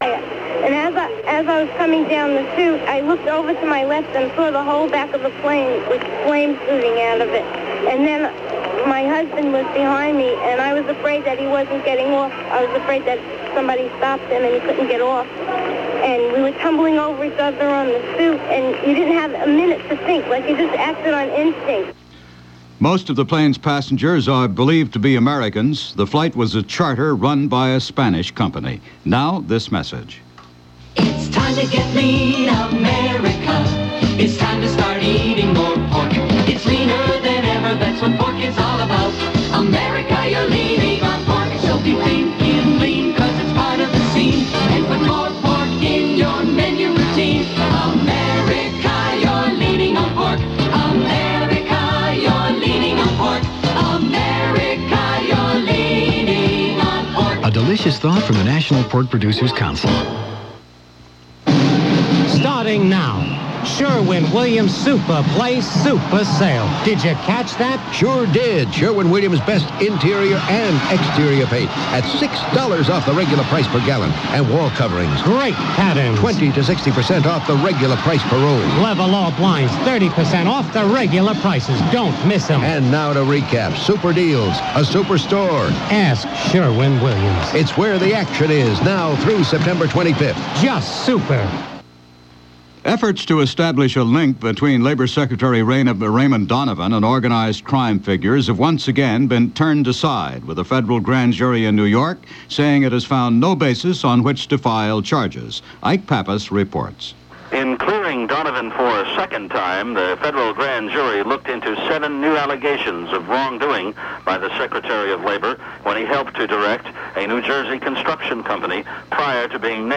September 13, 1982 – Busy news day – with reports of a Chartered DC-10 crashing on takeoff on a flight bound for New York from Malaga Spain, filled with returning American tourists. As the hours passed, and the newscasts updated each hour, the death toll steadily increased as questions were arising over just what was going on with DC-10’s. The tragedy in Malaga was one of what would be many such crashes involving DC-10’s – but only time would tell.